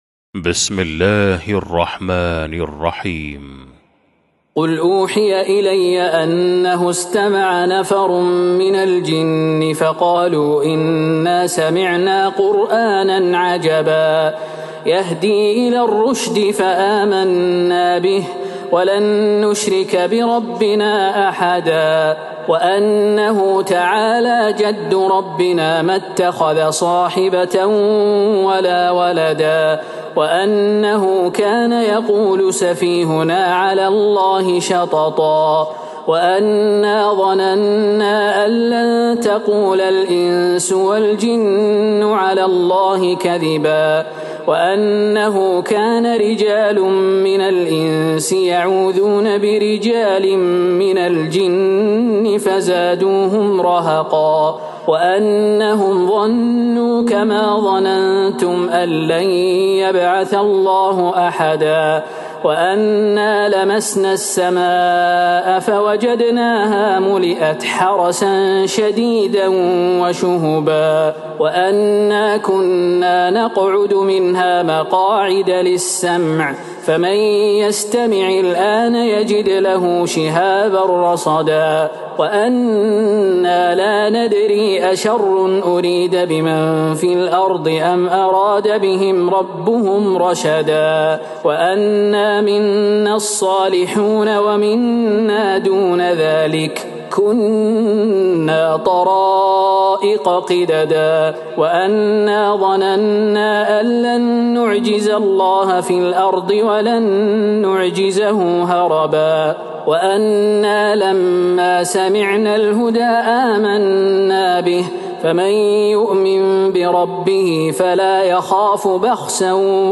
سورة الجن Surat Al-Jinn > مصحف تراويح الحرم النبوي عام ١٤٤٣ > المصحف - تلاوات الحرمين